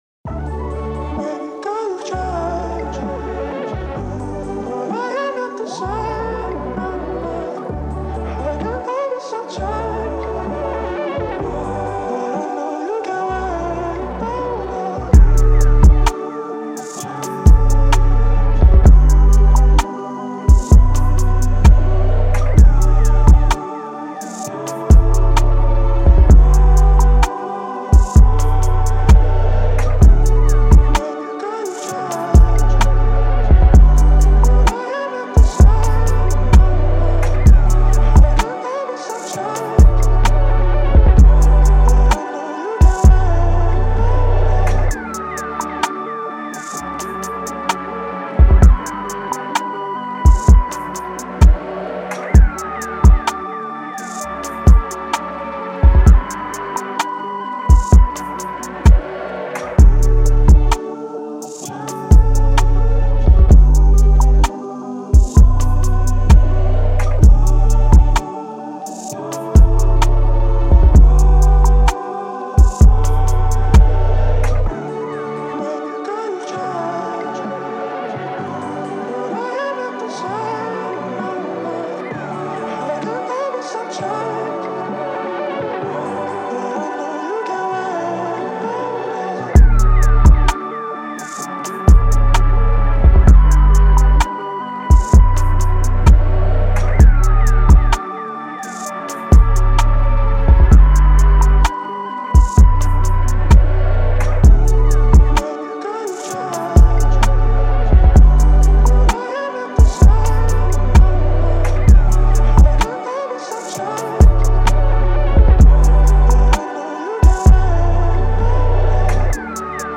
This is the official instrumental
2023 in Hip-Hop Instrumentals